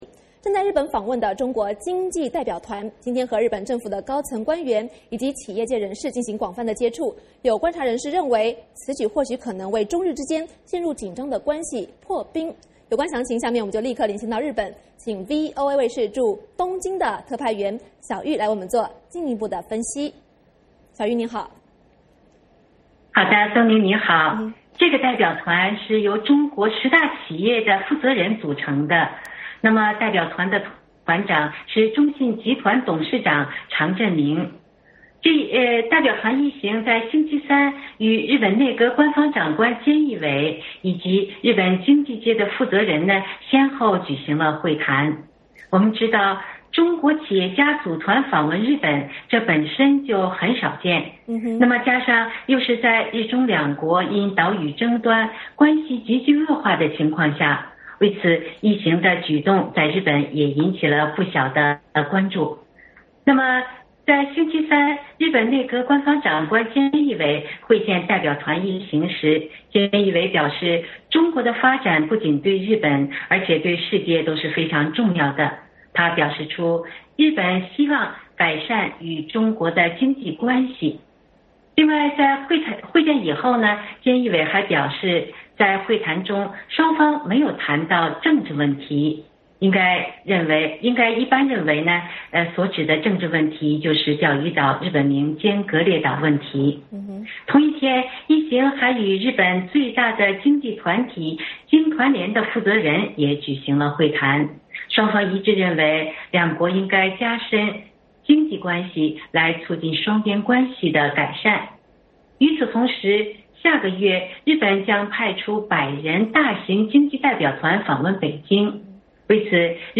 VOA连线：经济代表团访日 能否为中日关系破冰?